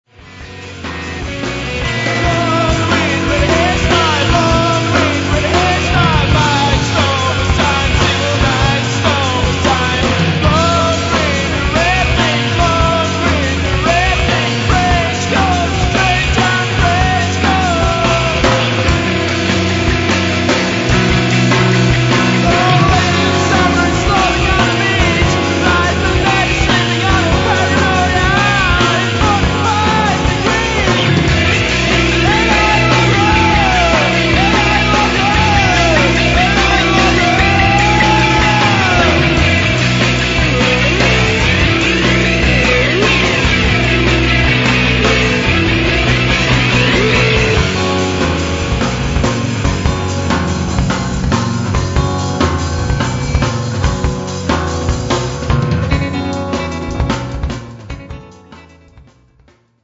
若手ポスト・ロックバンドのホープ